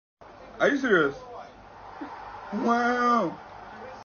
Are you serious (comedy comedy sound effect)
Download funny comedy sound effect or meme titled ”Are you serious” (comedy comedy sound/meme)
Are-you-serious-comedy-meme.mp3